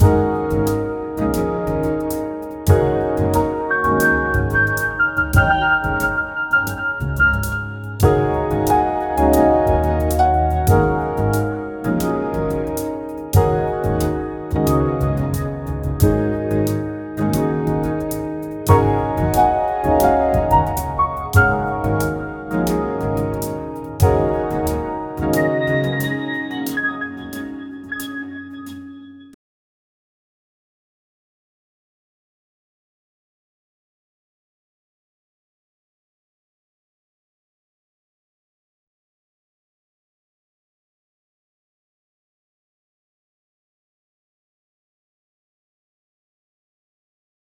sao_G#_Major_jazz_1.wav